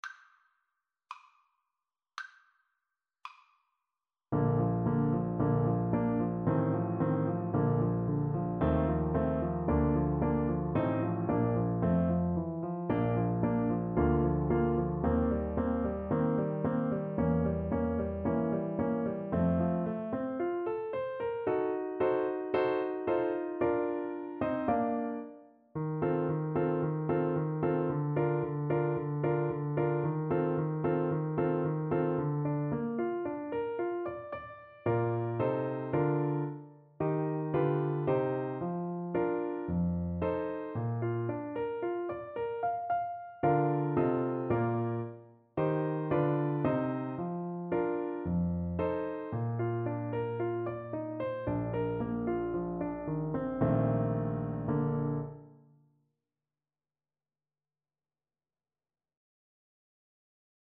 2/4 (View more 2/4 Music)
~ = 56 Affettuoso
Classical (View more Classical Clarinet Music)